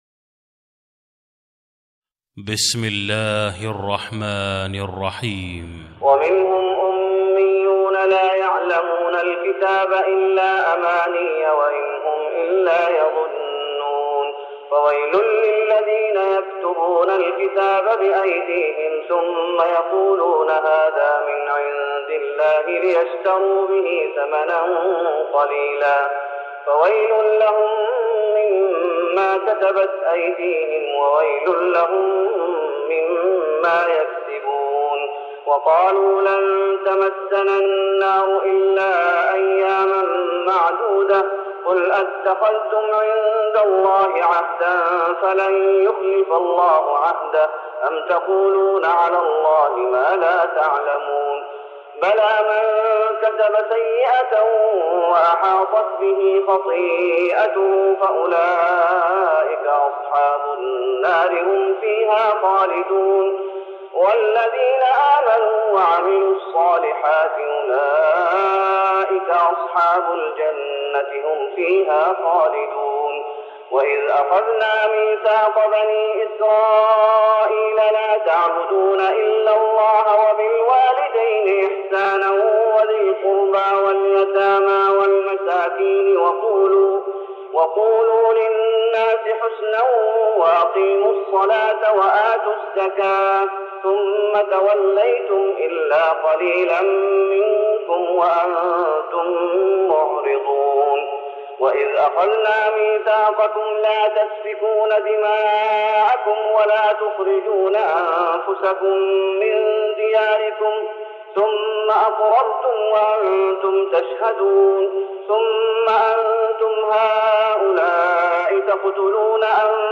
تراويح رمضان 1414هـ من سورة البقرة (78-141) Taraweeh Ramadan 1414H from Surah Al-Baqara > تراويح الشيخ محمد أيوب بالنبوي 1414 🕌 > التراويح - تلاوات الحرمين